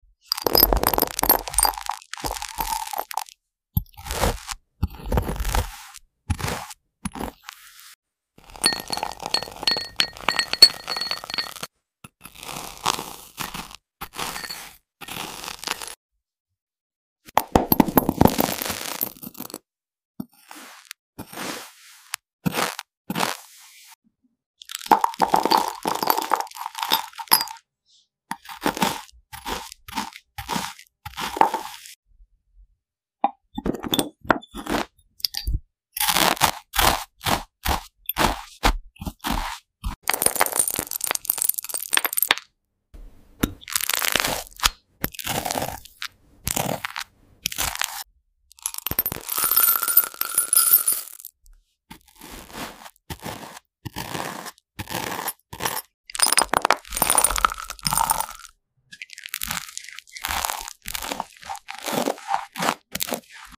Which Earth Spreading On Toast Sound Effects Free Download
Upload By Satisfying Slices ASMR